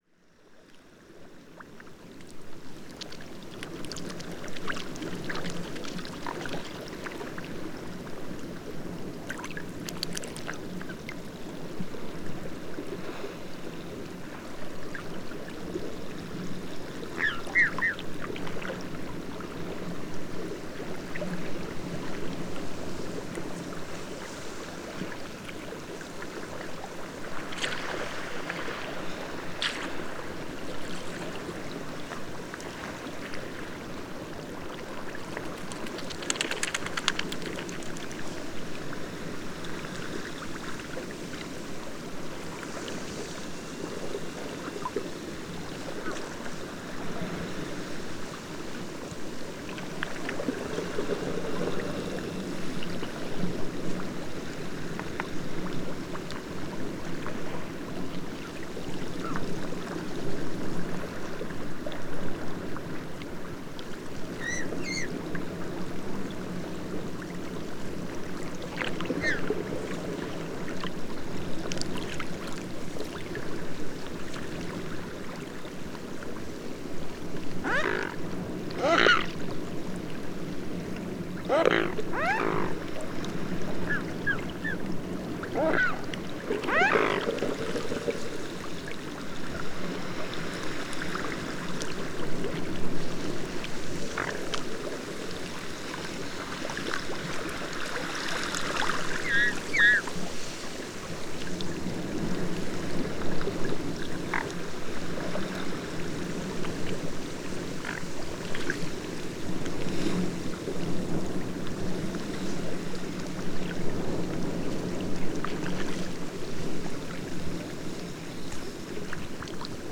101227, Mute Swan Cygnus olor, 1st winter and adults